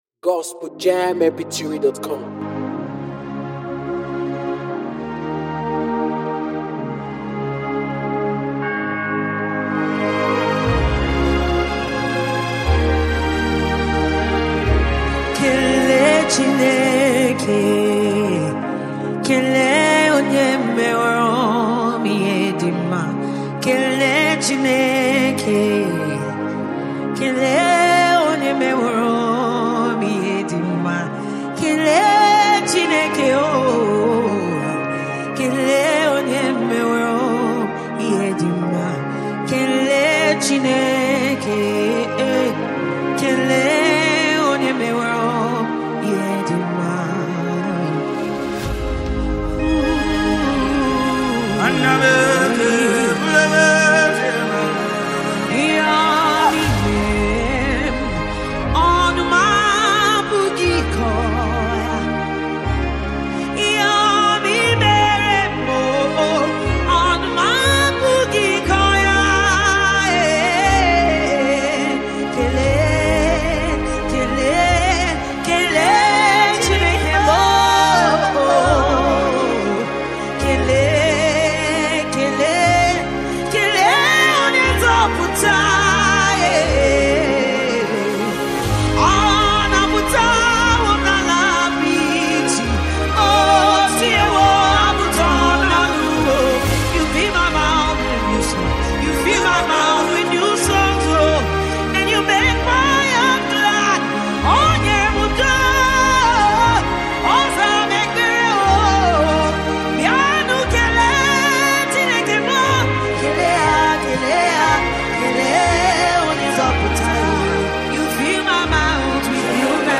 worship-anthem